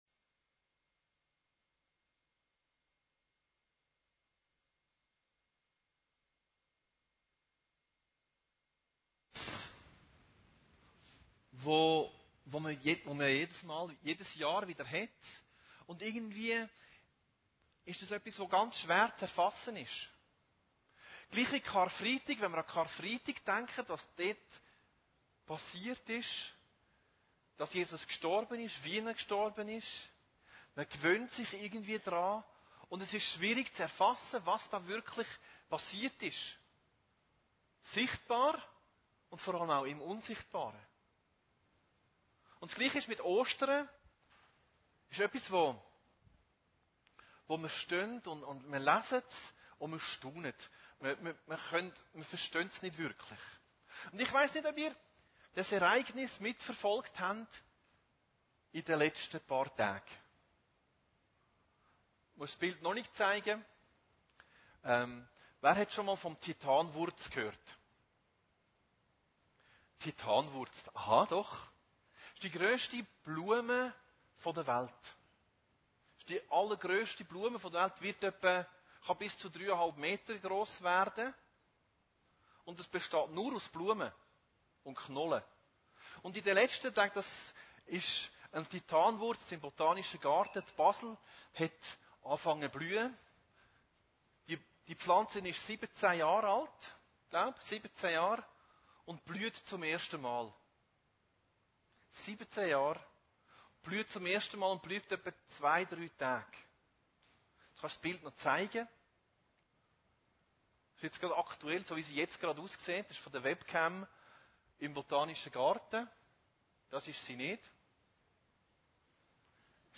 Predigten Heilsarmee Aargau Süd – Er Lebt